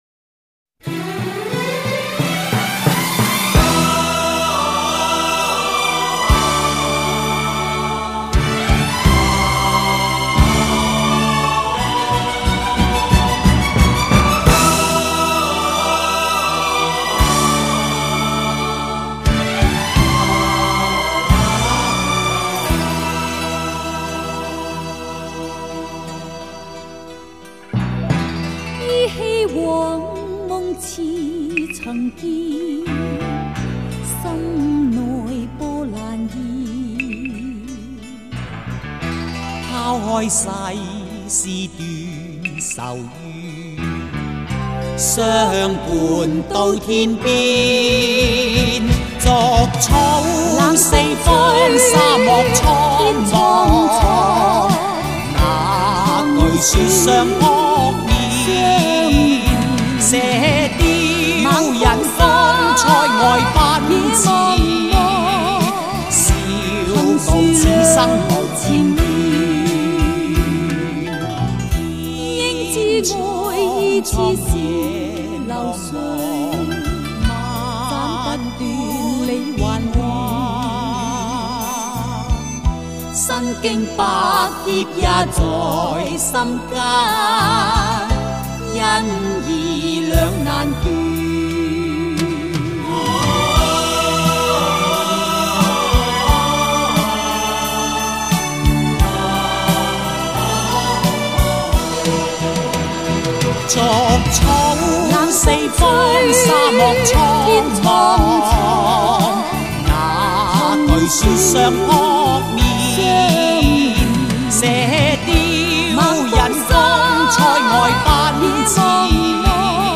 虽然配乐稍显老土，画面上的道具、布景、化装惨不忍睹，但我们缅怀的是那一份单纯的情愫。